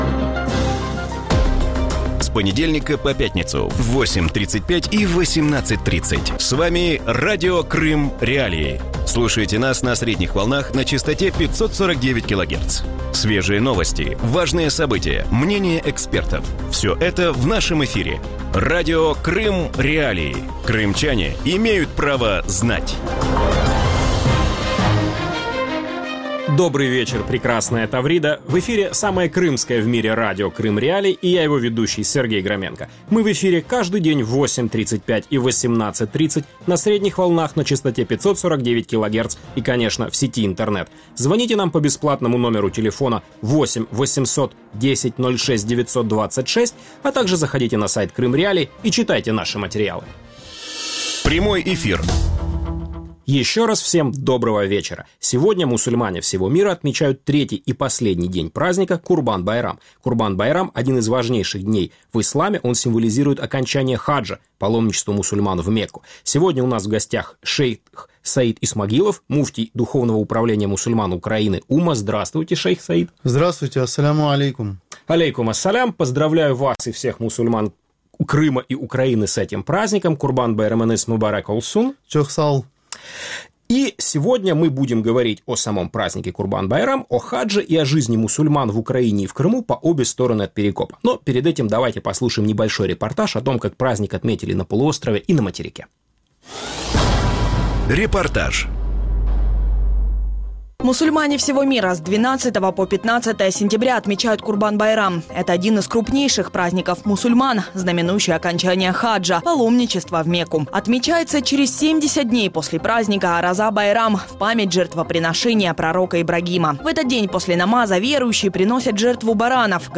У вечірньому ефірі Радіо Крим.Реалії обговорюють, як відзначають мусульманське свято Курбан-Байрам на материковій Україні і на півострові. Чи змінилися традиції святкування в Криму, як змінилася кількість паломників, що здійснюють хадж, і чи обмежують права мусульман на півострові?